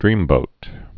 (drēmbōt)